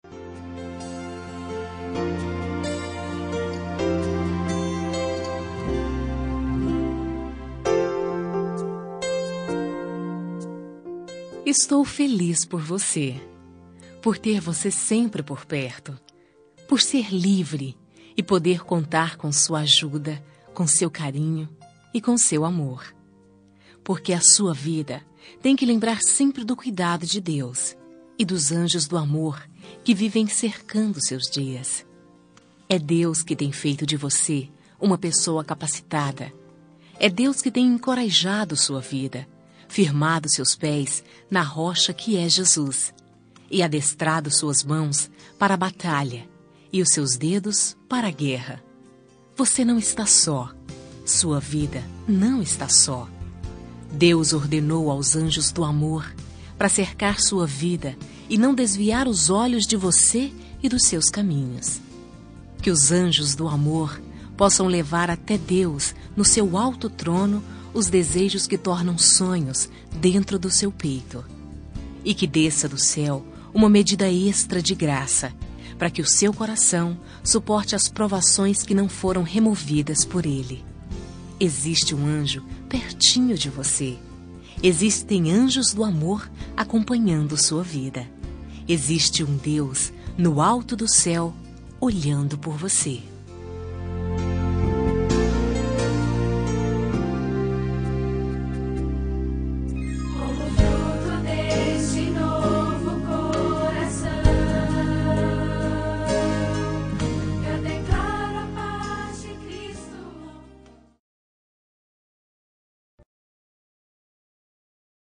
Aniversário Religioso – Voz Feminina – Cód: 34892